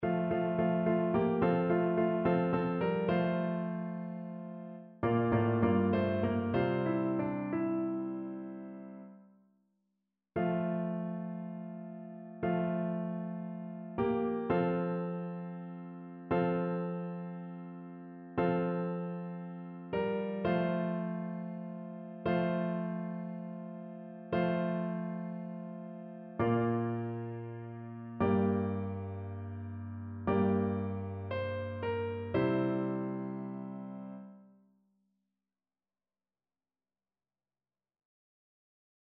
Chœur
annee-abc-temps-de-noel-nativite-du-seigneur-psaume-96-satb.mp3